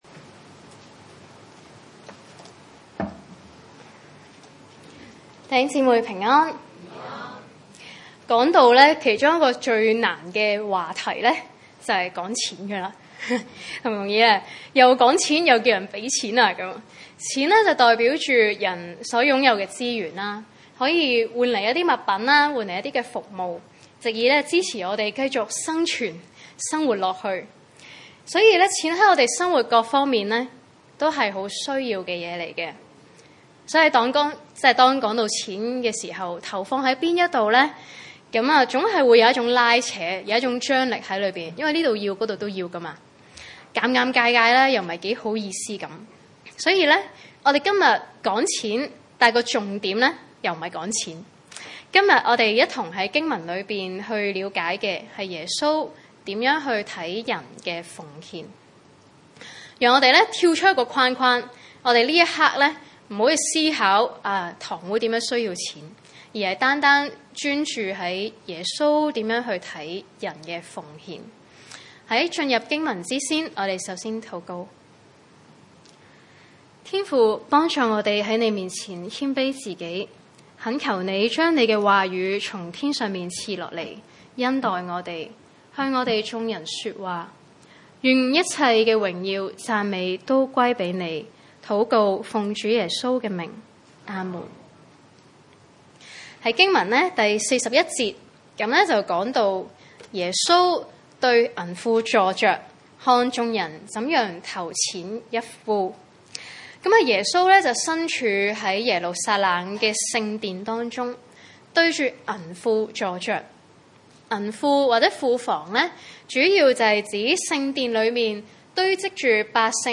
經文: 馬可福音12:41-44 崇拜類別: 主日午堂崇拜 41.